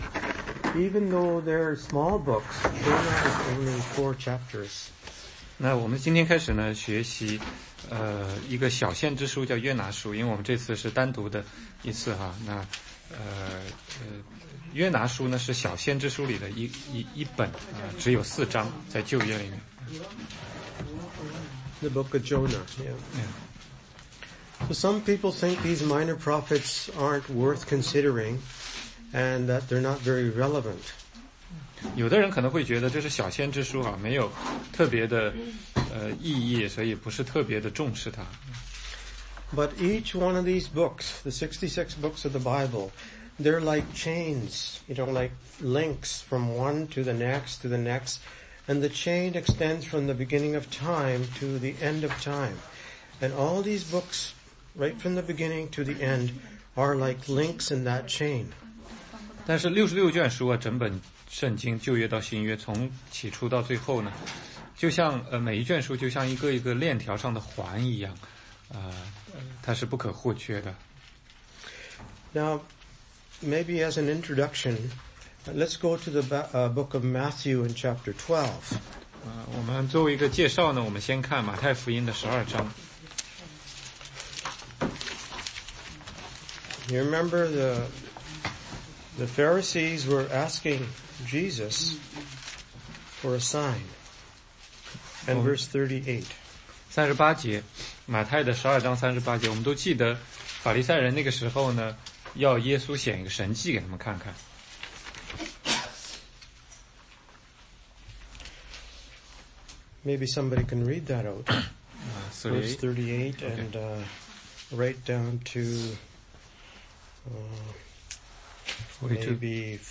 16街讲道录音 - 约拿书